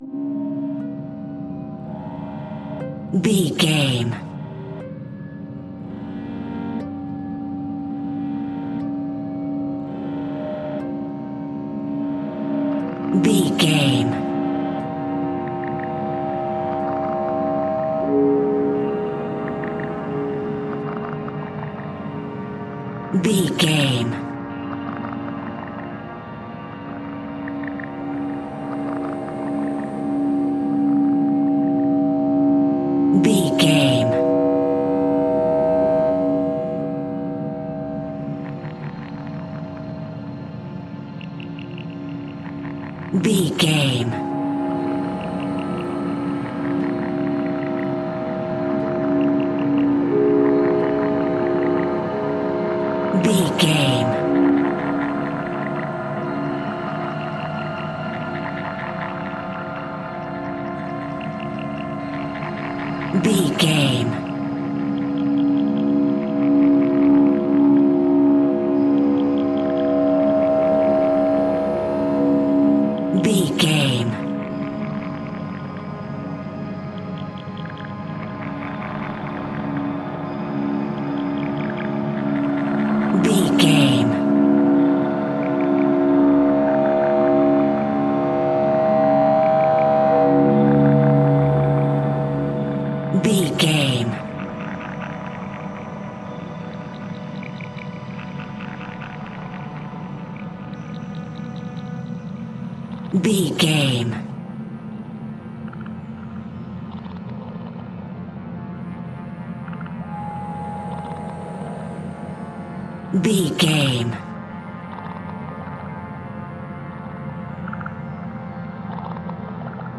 Atonal
Slow
scary
ominous
dark
haunting
eerie
synthesiser
keyboards
ambience
pads
eletronic